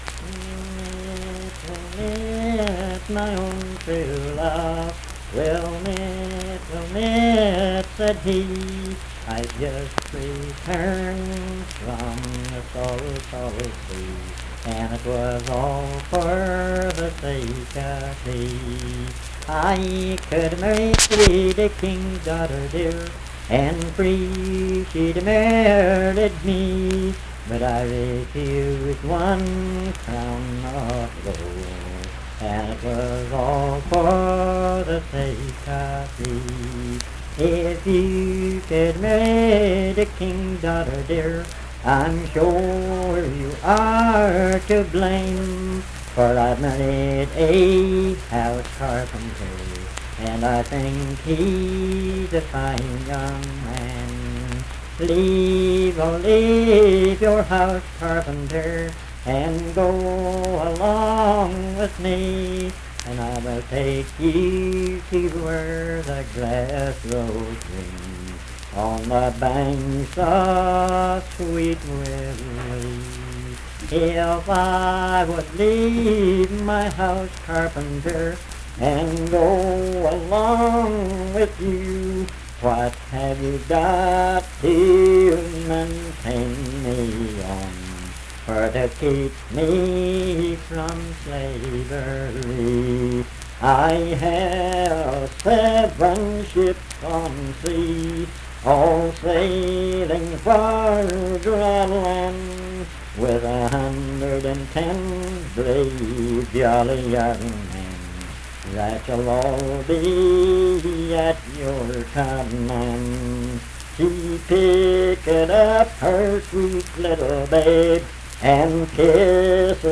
Unaccompanied vocal music
Verse-refrain 7(4).
Performed in Dundon, Clay County, WV.
Voice (sung)